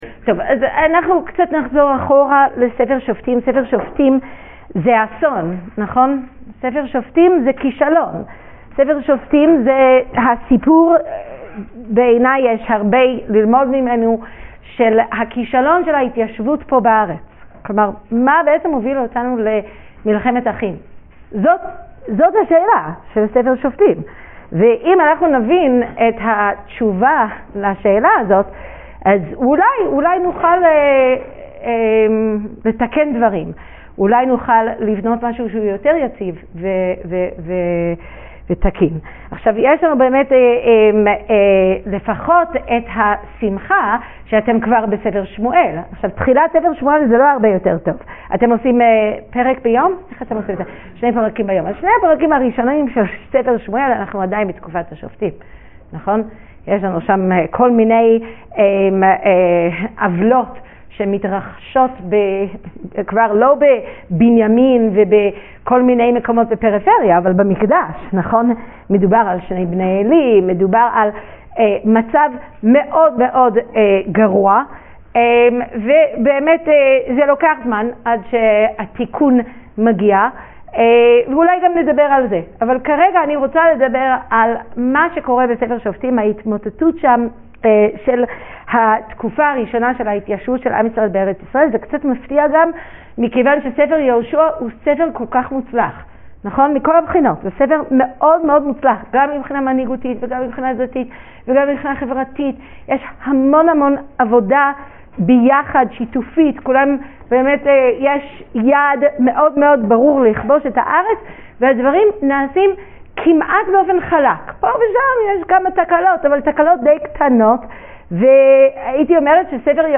שיעור שהועבר בפני תלמידי הישיבה בטו במר חשוון תשפ"ד